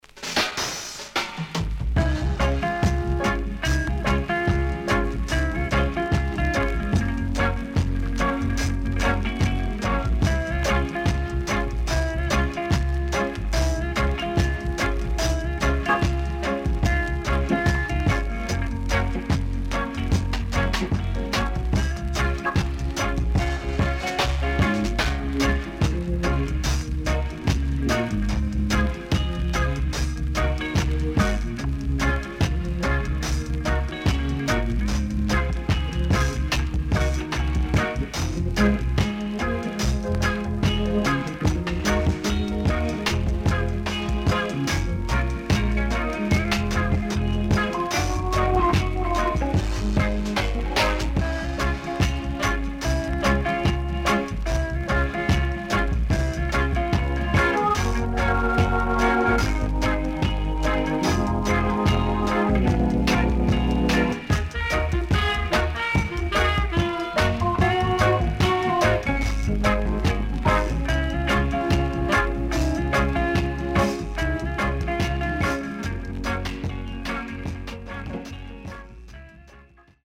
INST 70's
riddim
SIDE A:全体的にプレス起因のノイズ入ります。